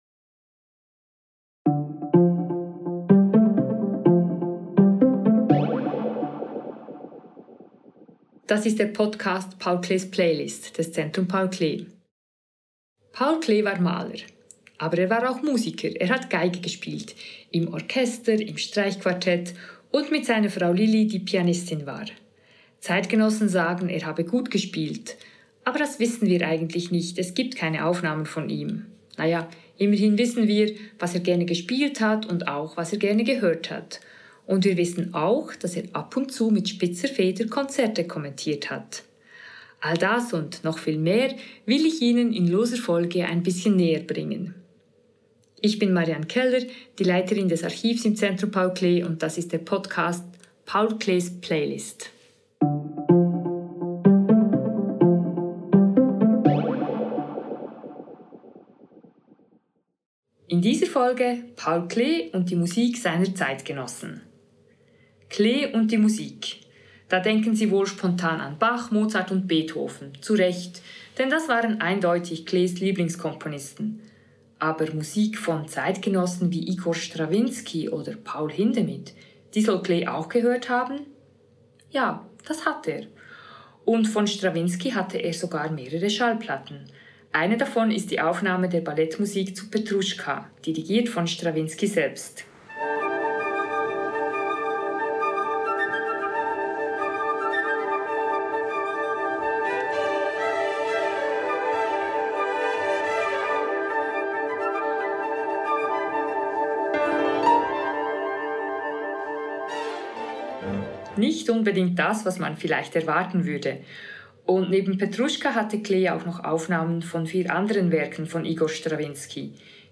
Gespielte Werke (Ausschnitte): Igor Strawinsky, Petruschka Paul Hindemith, Violinsonate Es-Dur op. 11 Nr. 1 Ferruccio Busoni, Albumblatt BV 272 Nr. 1 Ferruccio Busoni, Ouvertüre aus der Oper «Arlecchino» Max Reger, Violinsonate C-Dur op. 72 Sprecherin